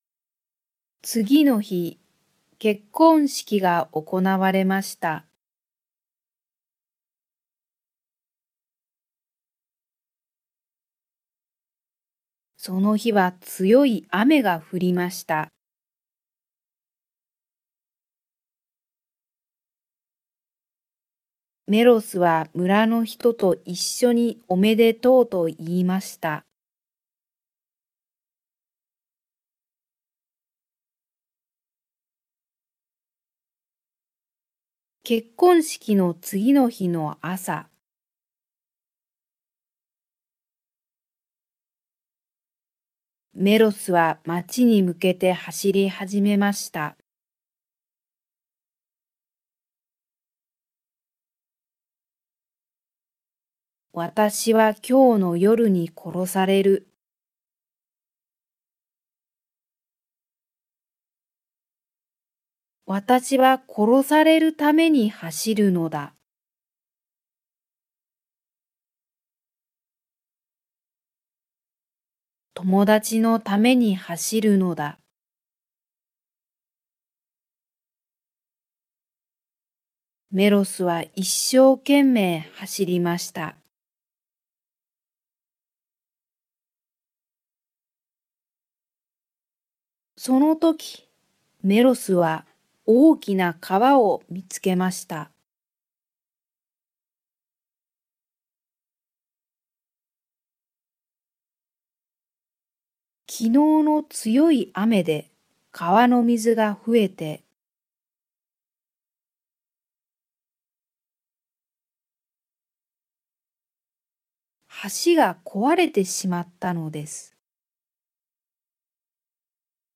Japanese Graded Readers: Fairy Tales and Short Stories with Read-aloud Method
Slow Speed
Slow Speed with Pauses